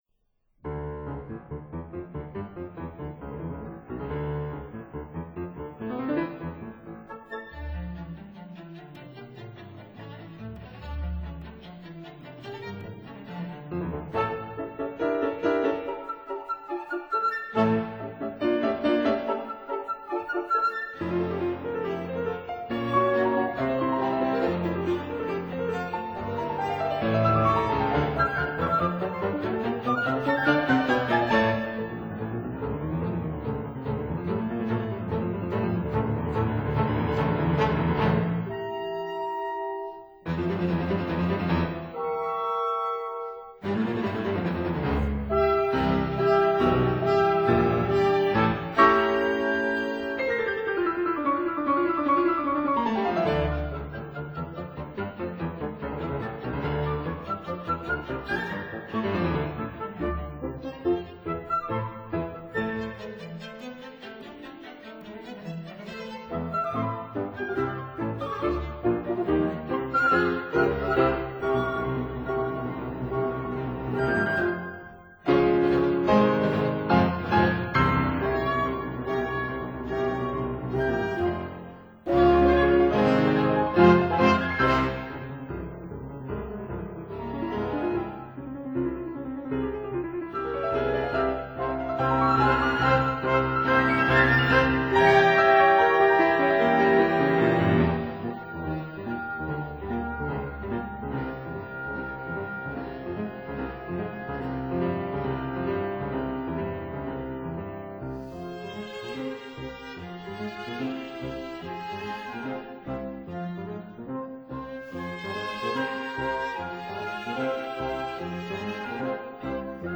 piano
violin
viola
cello
double bass
flute
oboe
bassoon
horn
trumpet